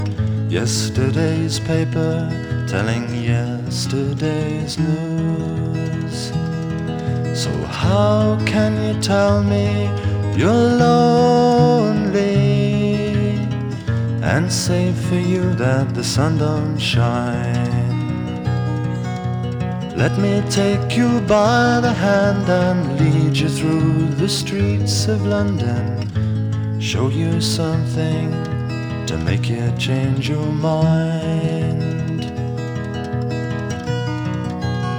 Жанр: Фолк-рок